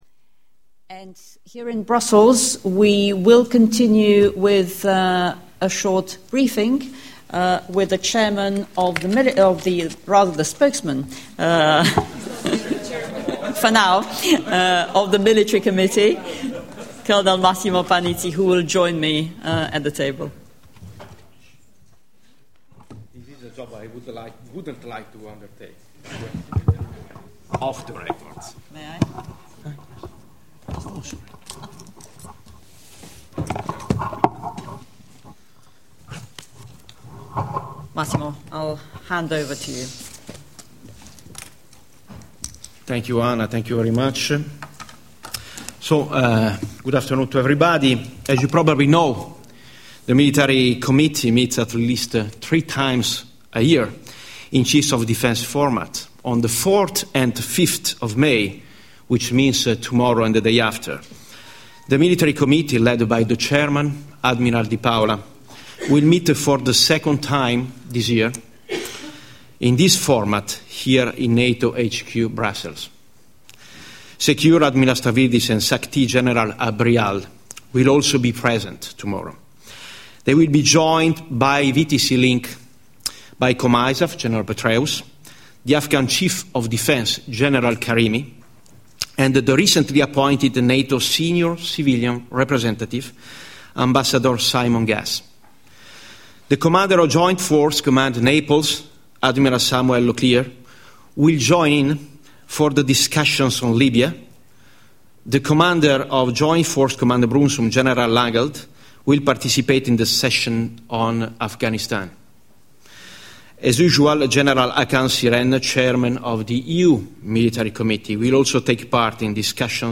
From the event Meeting of NATO and Partner Chiefs of Defence - 4&5 May 2011 Audio Joint Press briefing by the NATO Spokesperson, Oana Lungescu and the Spokesperson for the Military Committee, General Massimo Panizzi 03 May. 2011 | download mp3 Topics NATO Defense College